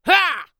CK重击2.wav
CK重击2.wav 0:00.00 0:00.60 CK重击2.wav WAV · 52 KB · 單聲道 (1ch) 下载文件 本站所有音效均采用 CC0 授权 ，可免费用于商业与个人项目，无需署名。
人声采集素材/男2刺客型/CK重击2.wav